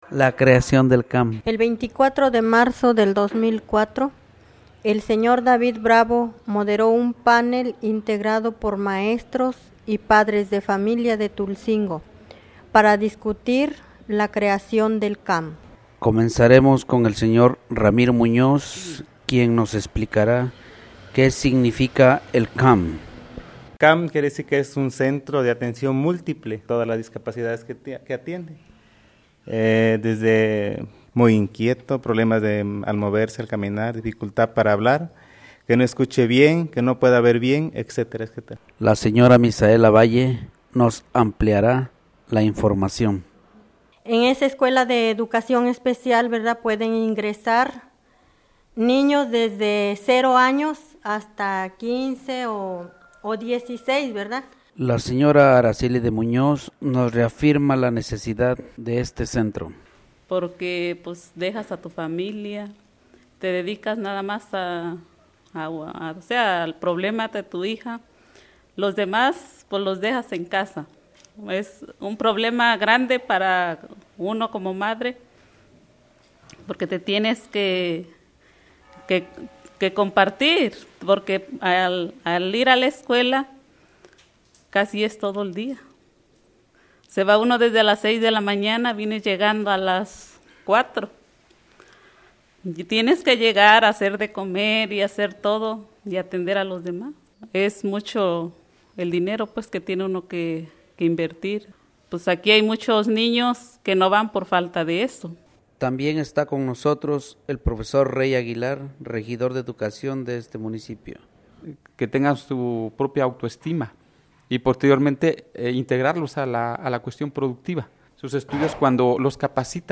discussion panel
formal & informative